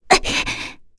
Frey-Vox_Damage_01.wav